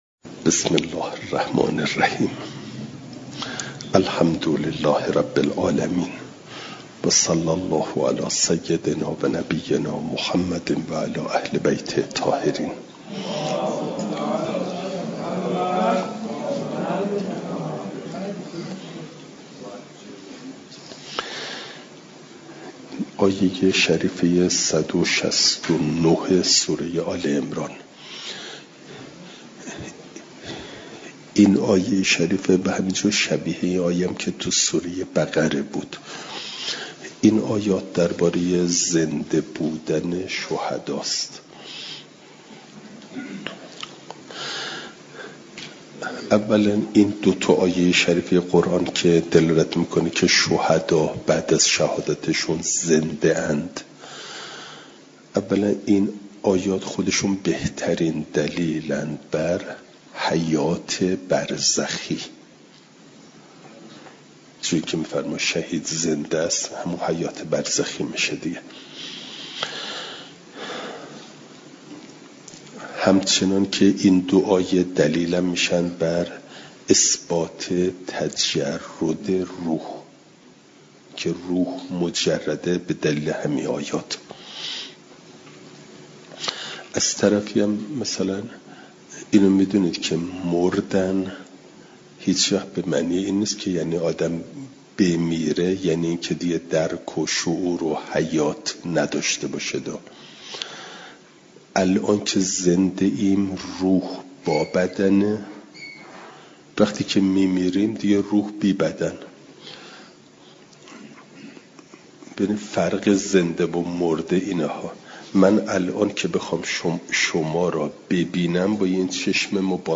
جلسه سیصد و بیست و سوم درس تفسیر مجمع البیان